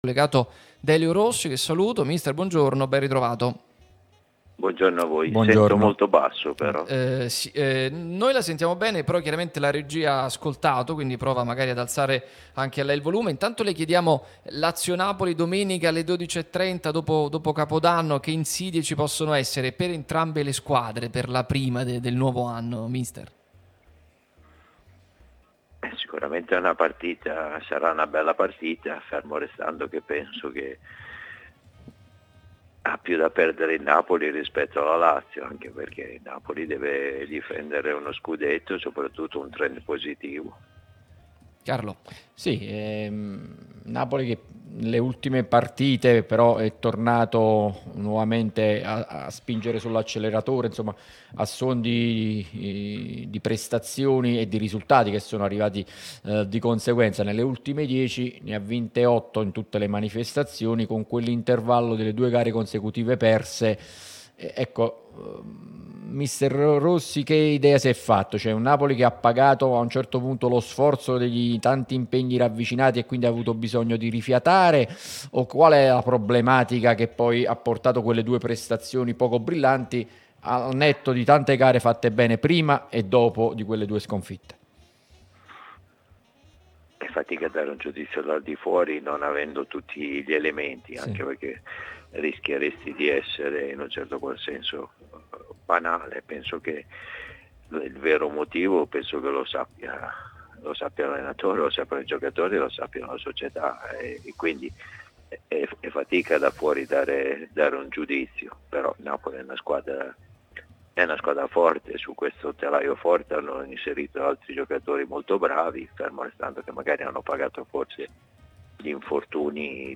Delio Rossi, ex allenatore della Lazio, è intervenuto nel corso di Pausa Caffè, trasmissione sulla nostra Radio Tutto Napoli, prima radio tematica sul Napoli, in onda tutto il giorno, che puoi ascoltare/vedere qui sul sito o sulle app (qui per Iphone/Ipad o qui per Android).